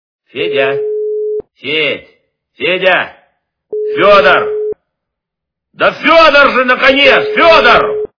» Звуки » Именные звонки » Именной звонок для Федька - Федя, Федь, Федя, Федор на конец Федор
При прослушивании Именной звонок для Федька - Федя, Федь, Федя, Федор на конец Федор качество понижено и присутствуют гудки.